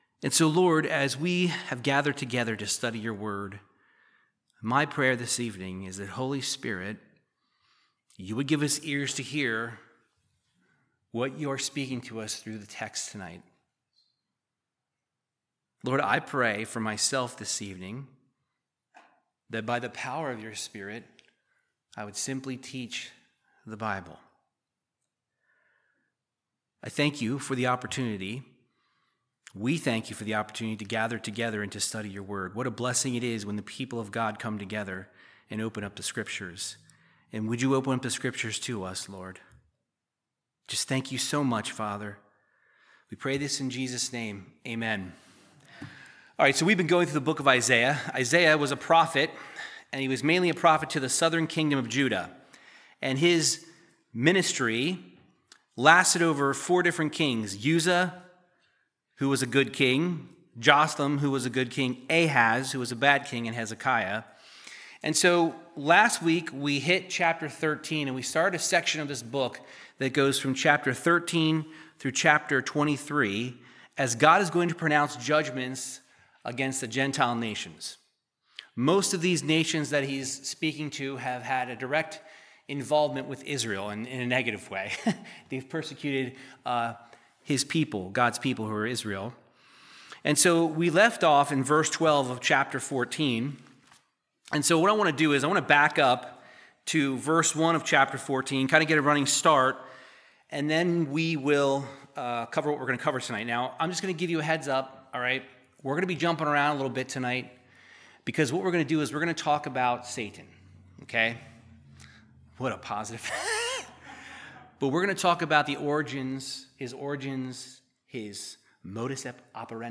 Verse by verse Bible teaching through the book of Isaiah 14 verses 12-32